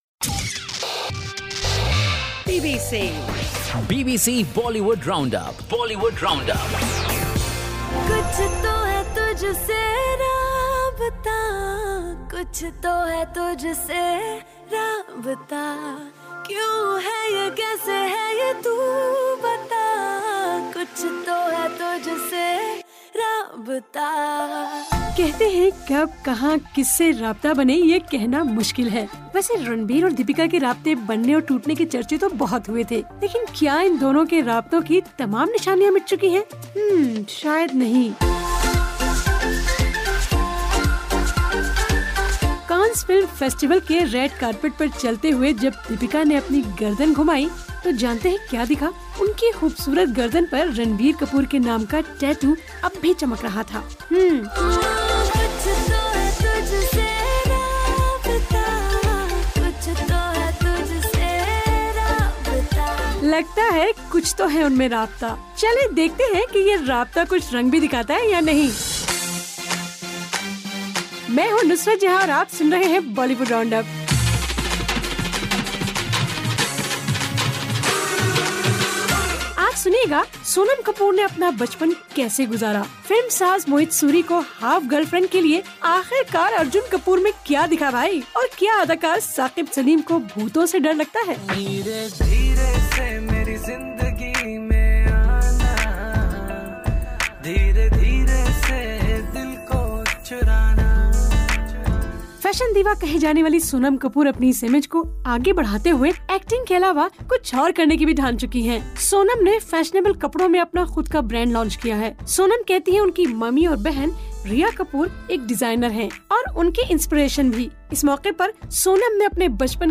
پرسنیلیٹی آف دی ویک میں سنیے مادھوری سے بات چیت